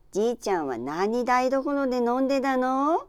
Aizu Dialect Database
Type: Single wh-question
Final intonation: Rising
WhP Intonation: Peak
Location: Aizuwakatsu/会津若松市
Sex: Female